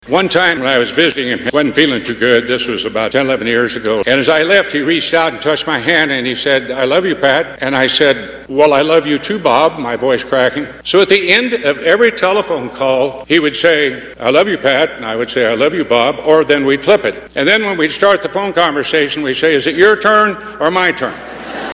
Former Senator Pat Roberts was there to speak about Dole, not only as a politician, but as a friend.